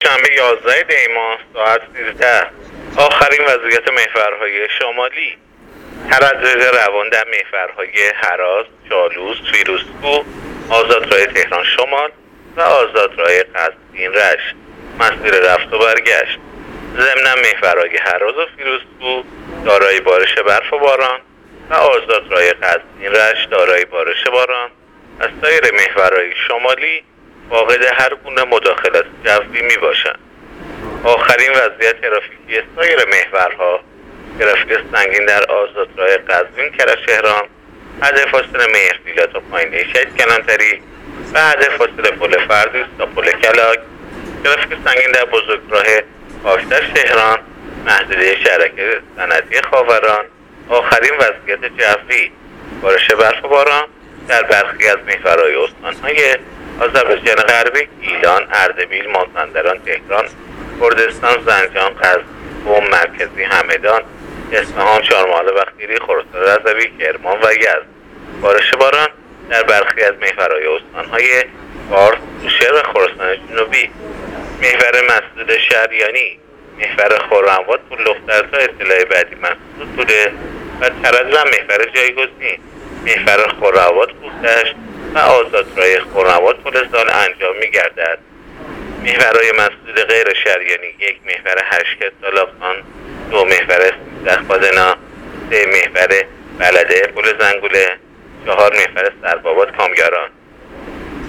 گزارش رادیو اینترنتی از آخرین وضعیت ترافیکی جاده‌ها تا ساعت ۱۳ یازدهم دی؛